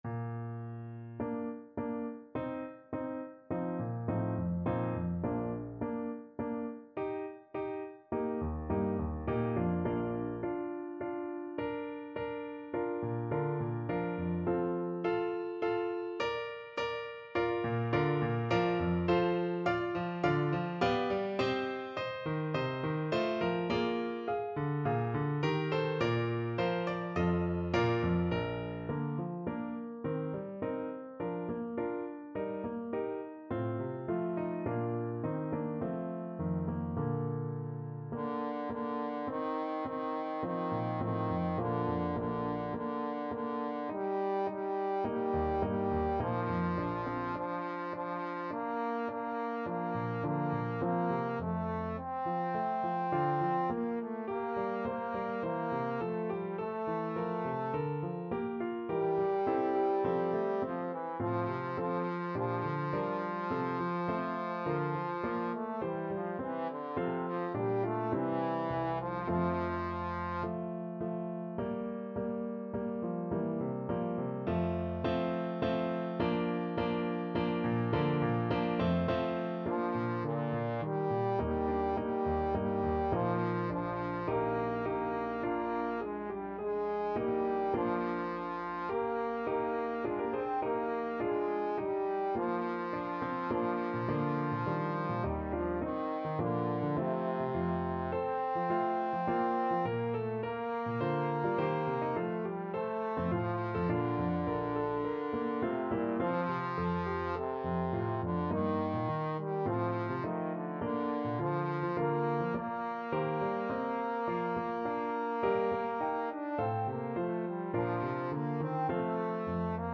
Trombone
4/4 (View more 4/4 Music)
Bb major (Sounding Pitch) (View more Bb major Music for Trombone )
Larghetto (=c.52)
Classical (View more Classical Trombone Music)
handel_as_with_rosy_steps_TBNE.mp3